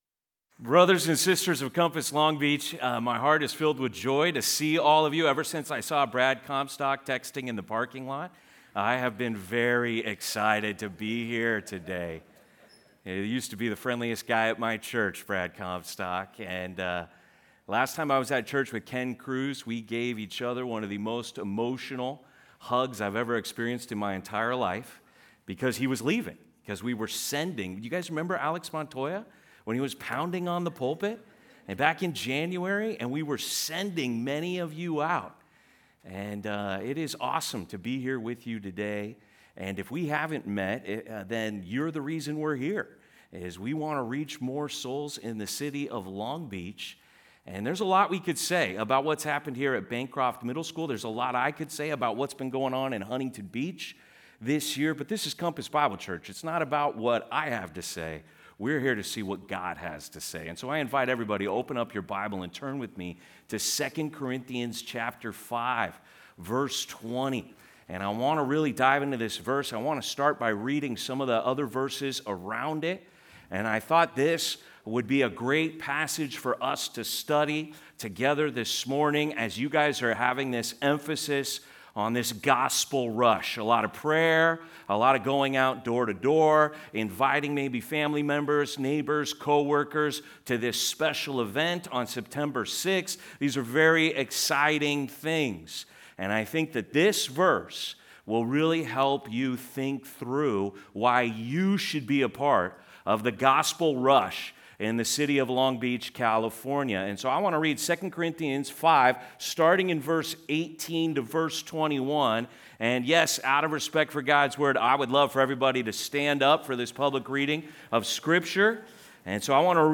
To the Ambassadors of Long Beach (Sermon) - Compass Bible Church Long Beach